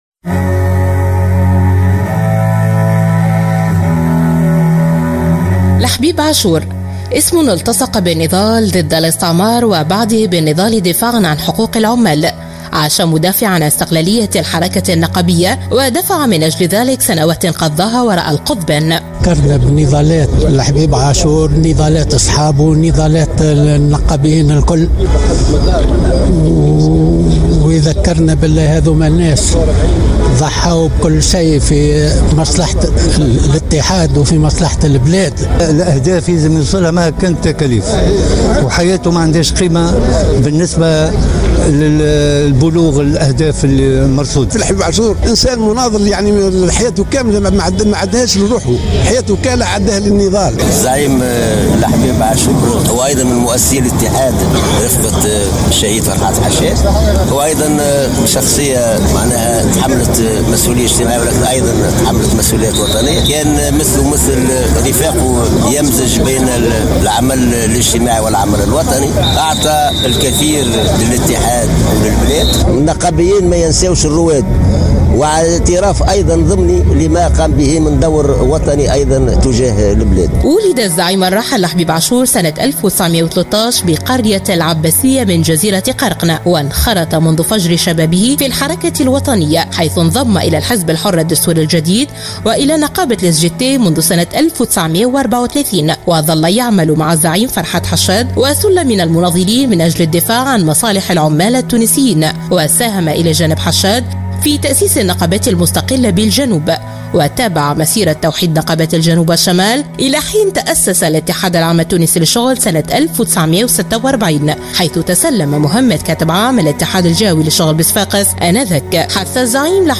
تقرير الحبيب عاشور تحميل المشاركة علي مقالات أخرى وطنية 22/03